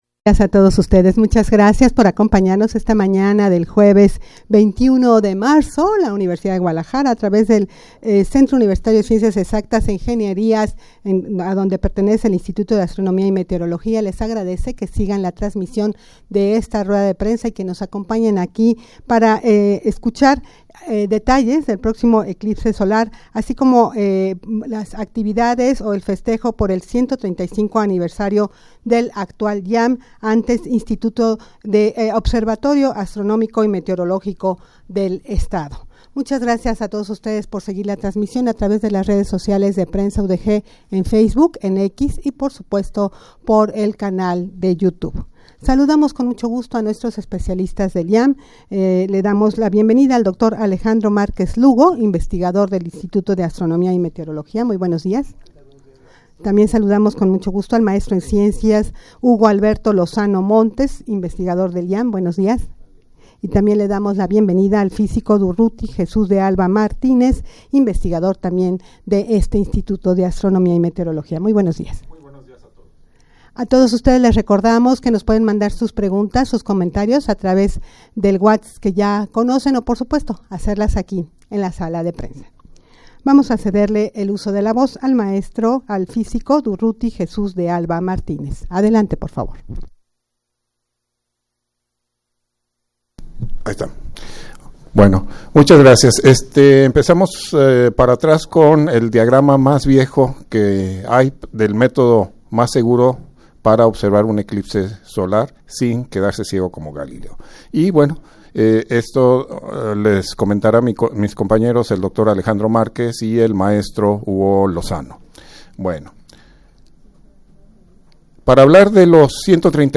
Audio de la Rueda de Prensa
rueda-de-prensa-para-hablar-del-proximo-eclipse-solar-asi-como-de-las-actividades-por-el-135-aniversario-del-iam.mp3